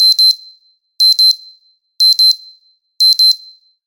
دانلود صدای هشدار موبایل 1 از ساعد نیوز با لینک مستقیم و کیفیت بالا
جلوه های صوتی